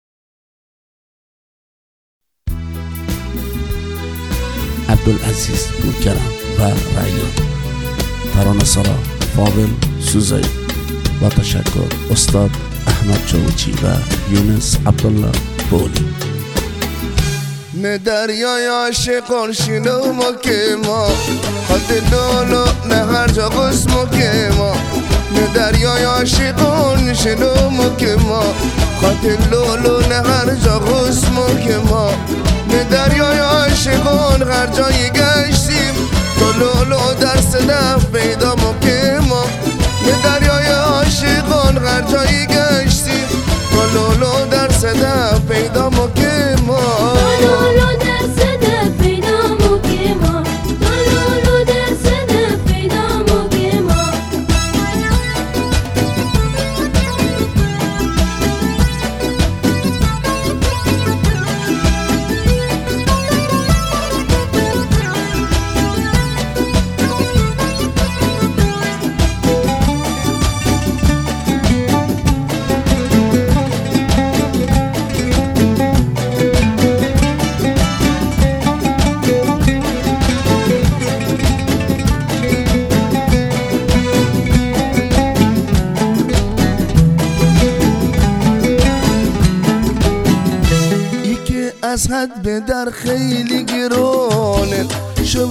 آهنگ بستکی